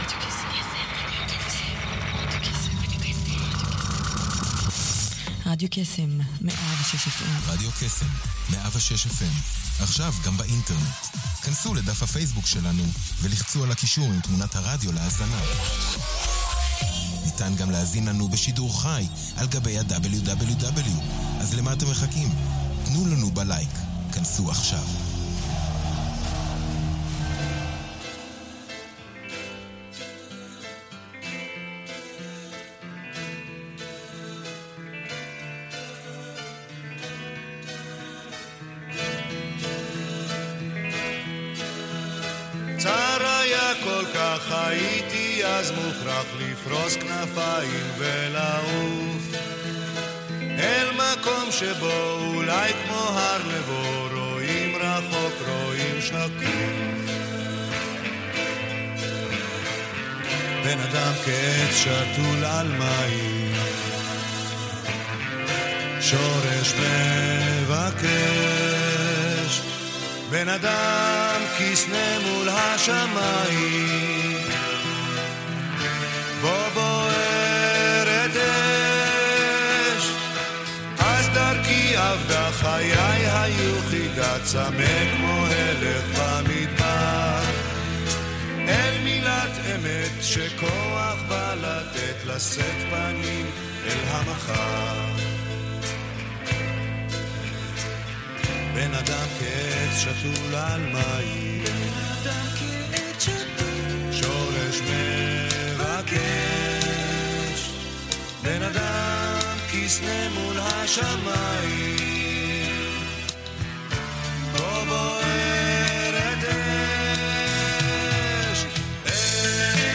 במקומה, שודרה שעה של שירים עבריים יפים ואהובים.